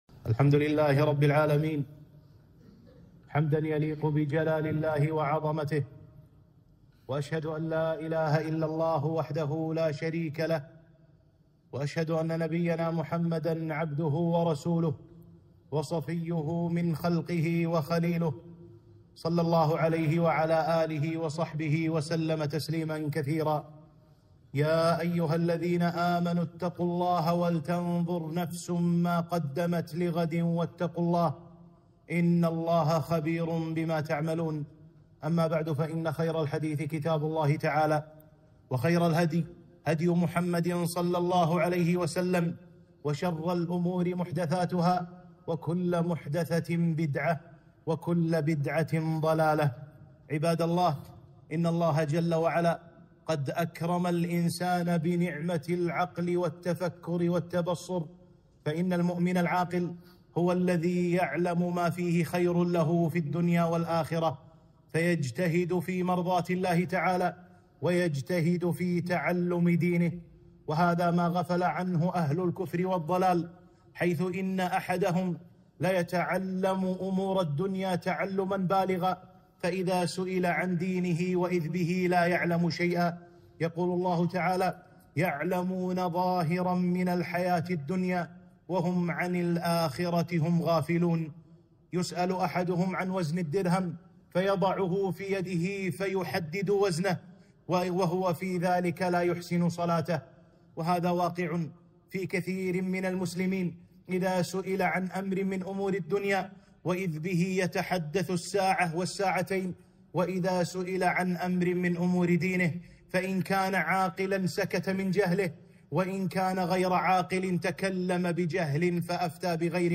خطبة - عبادة الوضوء، فضائل ومسائل